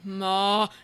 Tag: 声乐 沮丧 声音